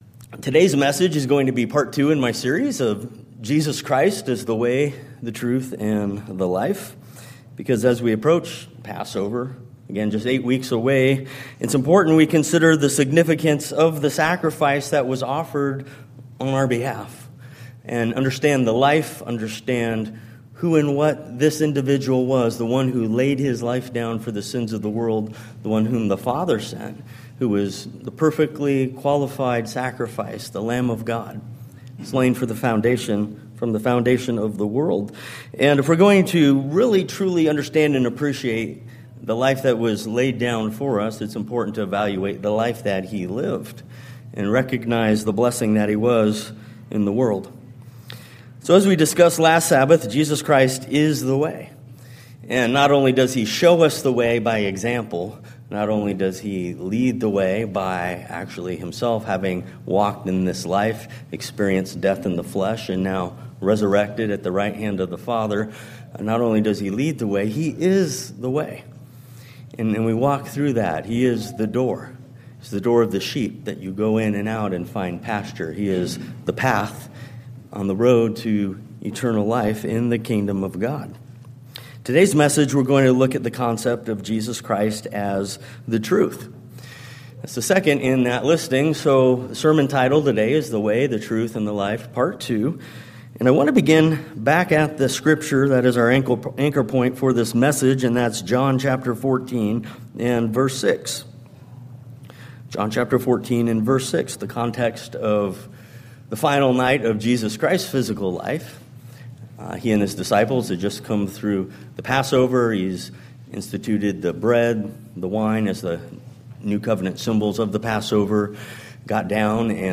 This is part 2 in the sermon series with a focus on Jesus Christ as “the truth.” Jesus Christ not only spoke the truth but also lived it, and those who are of the truth see that He is true.